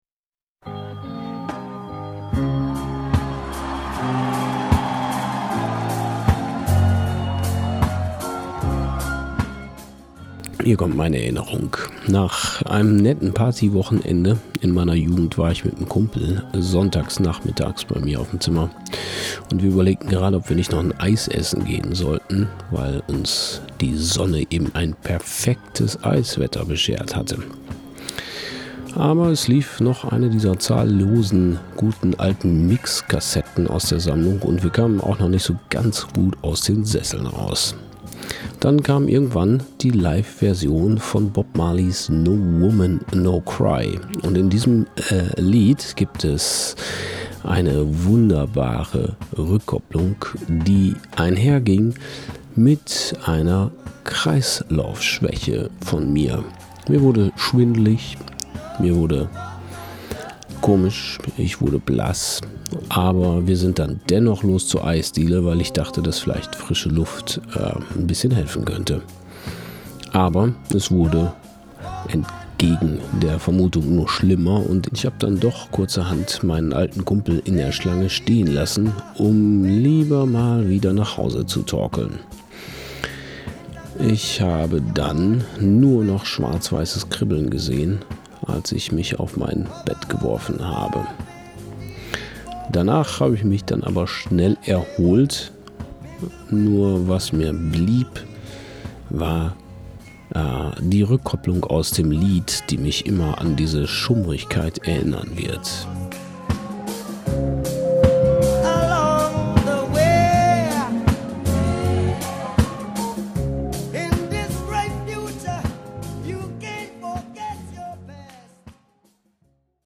Geschlecht: männlich